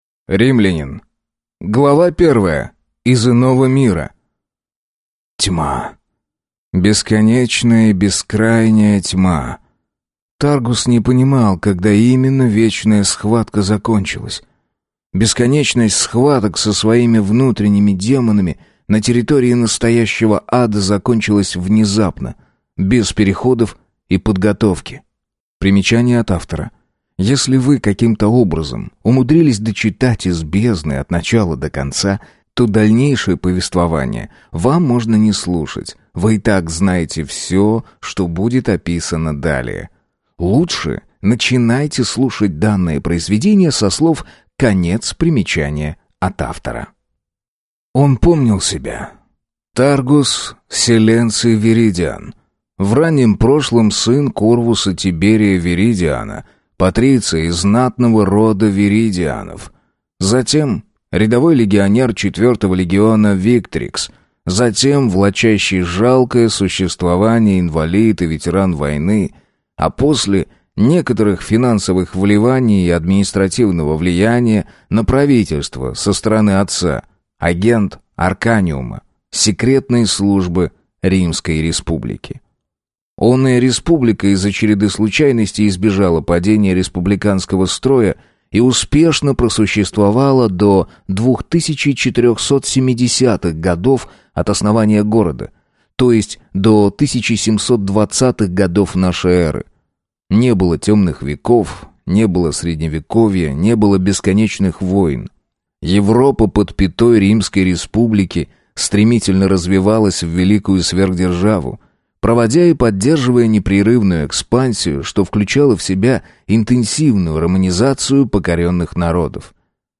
Аудиокнига Римлянин | Библиотека аудиокниг
Прослушать и бесплатно скачать фрагмент аудиокниги